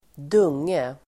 Ladda ner uttalet
Uttal: [²d'ung:e]
dunge.mp3